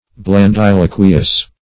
Search Result for " blandiloquious" : The Collaborative International Dictionary of English v.0.48: Blandiloquous \Blan*dil"o*quous\, Blandiloquious \Blan*di*lo"qui*ous\, a. Fair-spoken; flattering.
blandiloquious.mp3